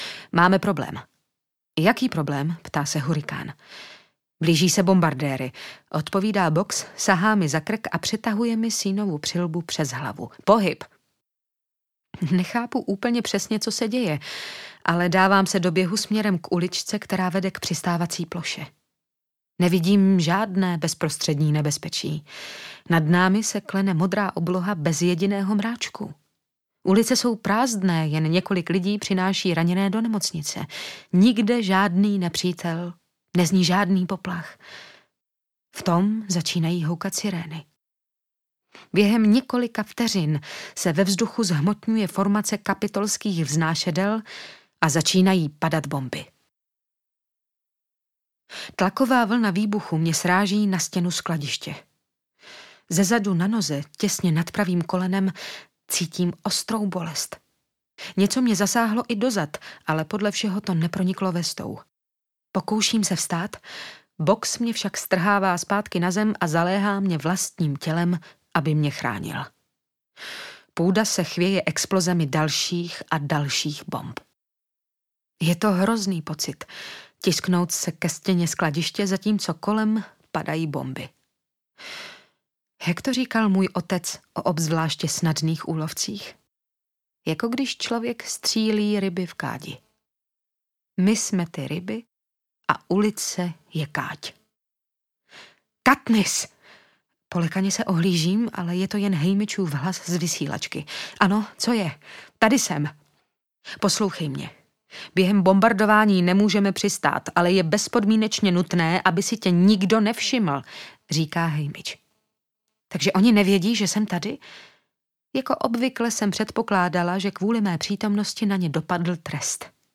Hunger Games 3 - Síla vzdoru audiokniha
Ukázka z knihy
Zaposlouchejte se do podmanivého hlasu Terezy Bebarové a nechte se vtáhnout do dobrodružství, ve kterém jde o všechno.
• InterpretTereza Bebarová